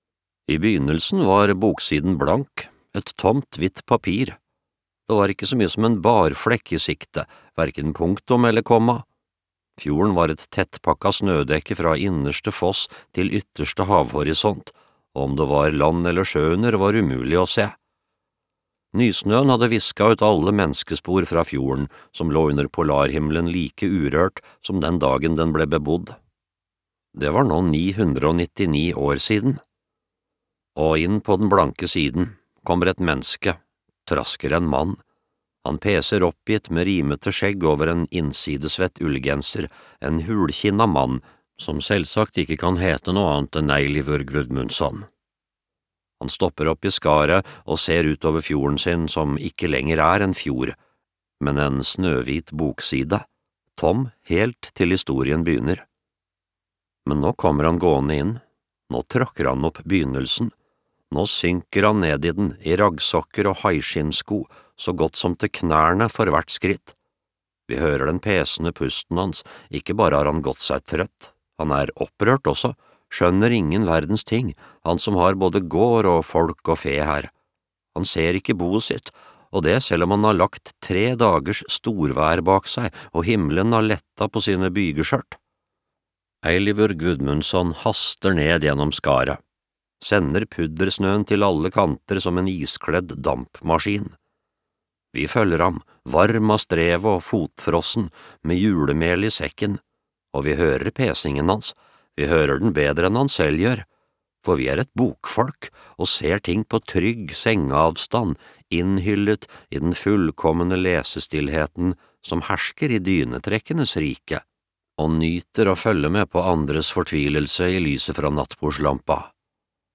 Seksti kilo solskinn (lydbok) av Hallgrímur Helgason